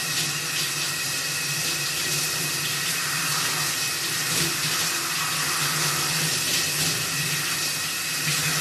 shower.mp3